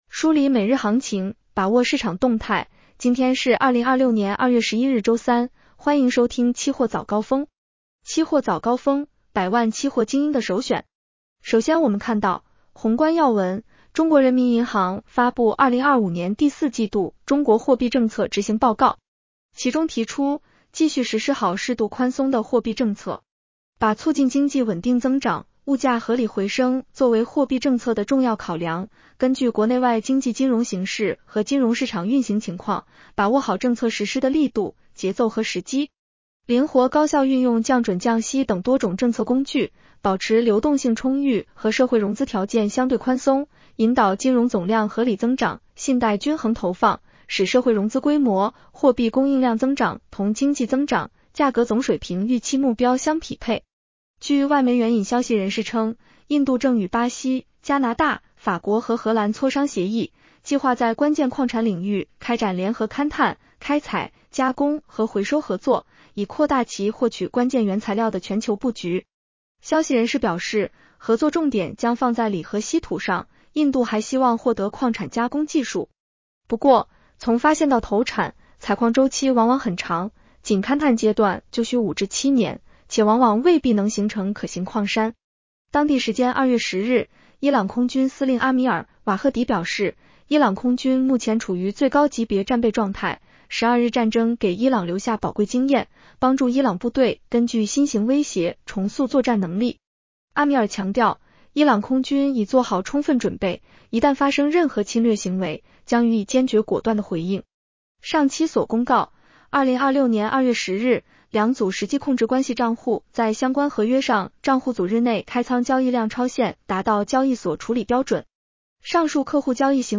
期货早高峰-音频版
期货早高峰-音频版 女声普通话版 下载mp3 热点导读 1.中国人民银行发布2025年第四季度中国货币政策执行报告。